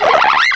sovereignx/sound/direct_sound_samples/cries/dewott.aif at master